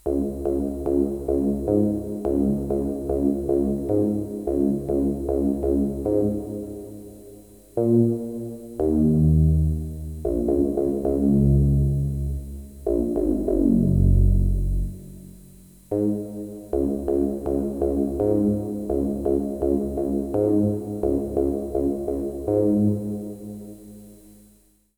Ce n'est pas au C mais sur le TI2: